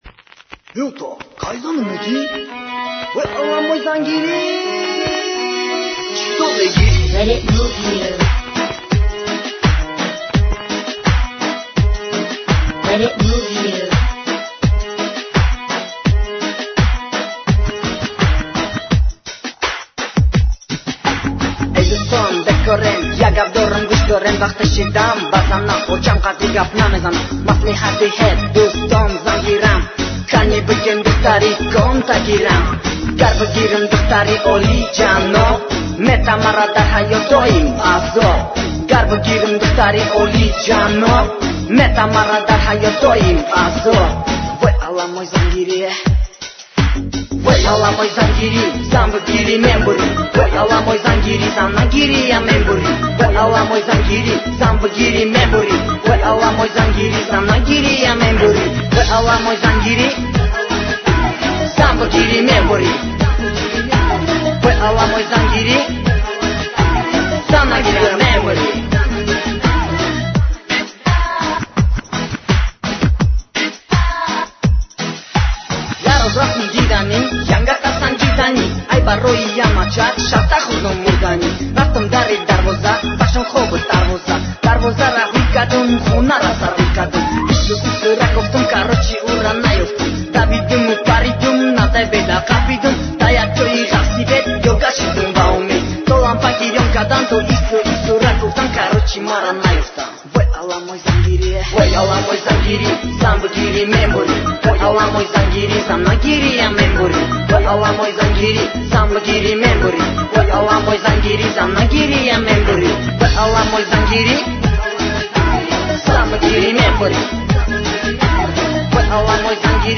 حالا ااین شعرو به صورت موزیک بشنوید ولی با یه کم دستکاری توی سبک اجرا یعنی به صورت رپ و با لهجه تاجیکی